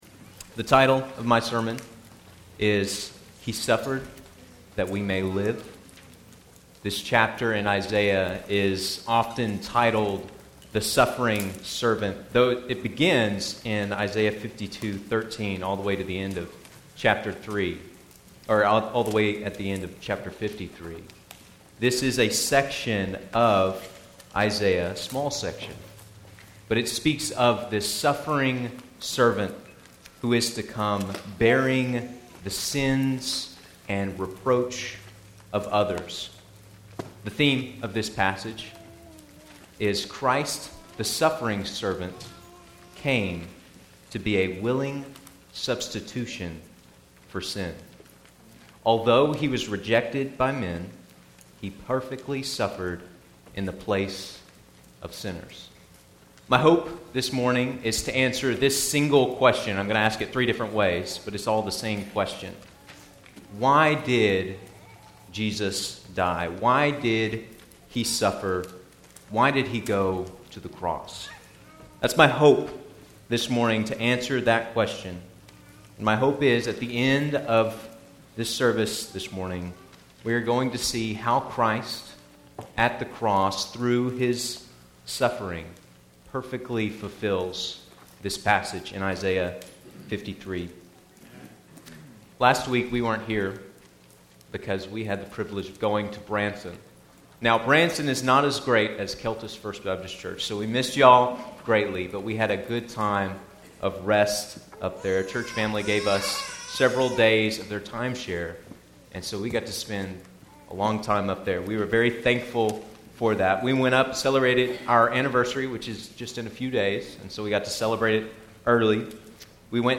Keltys Worship Service, December 14, 2025